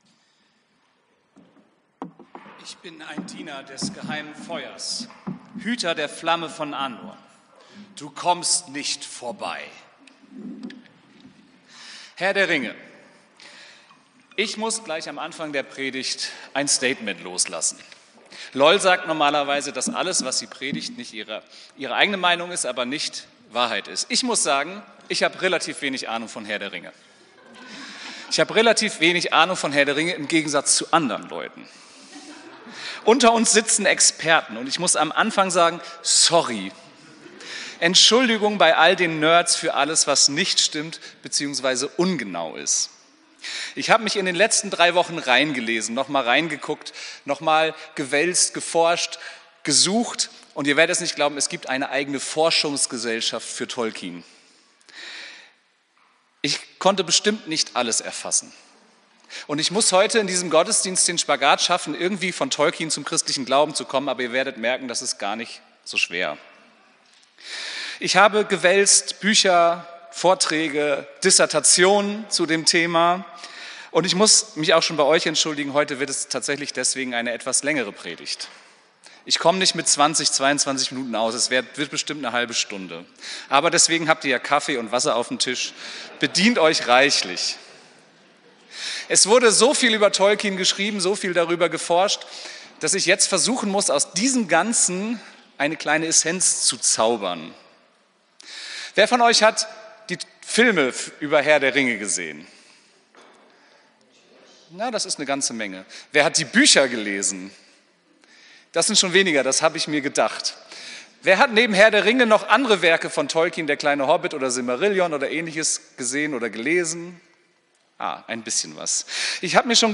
Predigt vom 11.05.2025